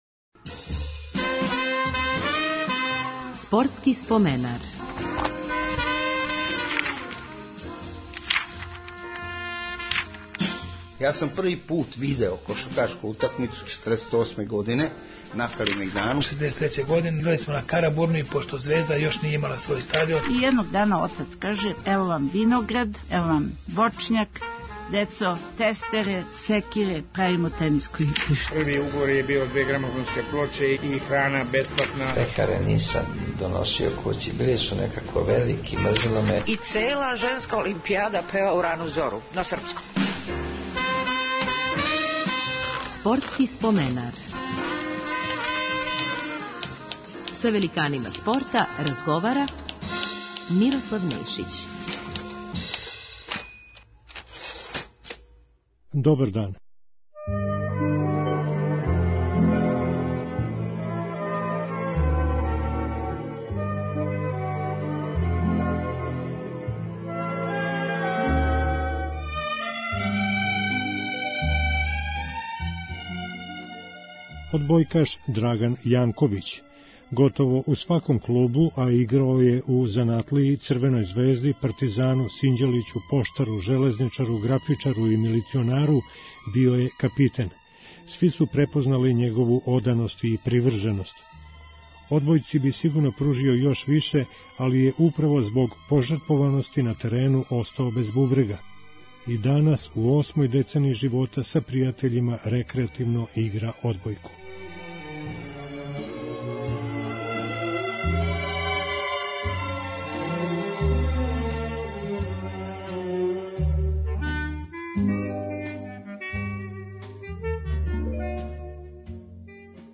Радио Београд Први програм од 16 до 17 часова.